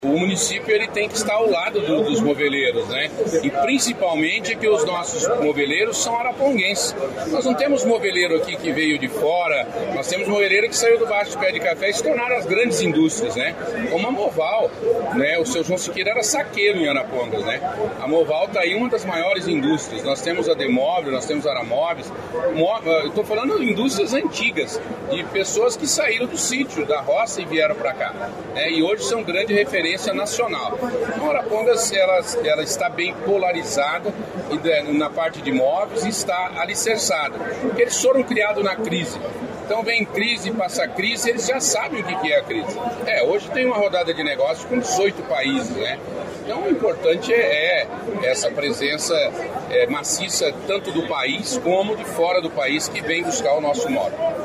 Sonora do prefeito de Arapongas, Sérgio Onofre, sobre a Movelpar Home Show 2024 | Governo do Estado do Paraná